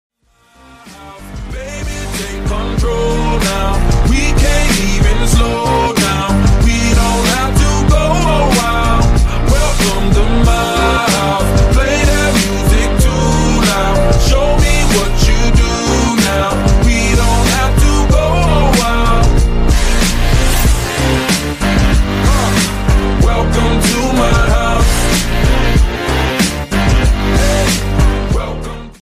Dance Ringtones